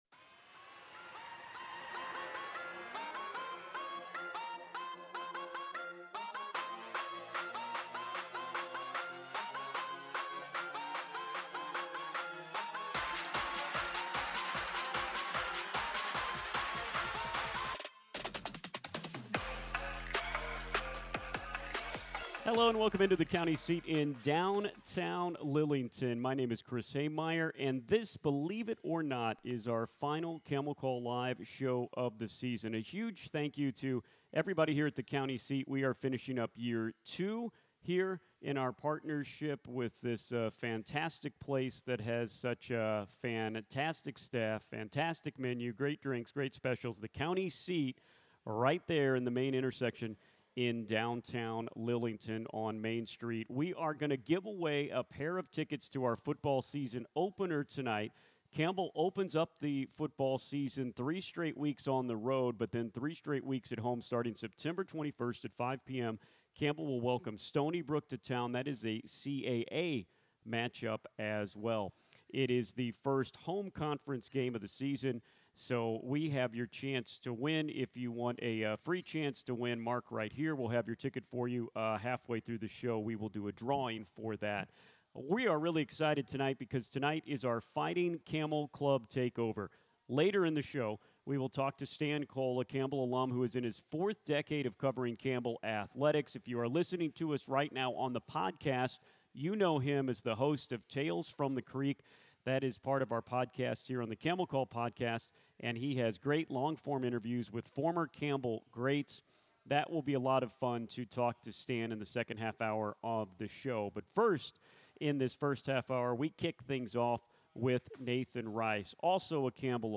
Recorded live on May 20th from the County Seat in downtown Lillington.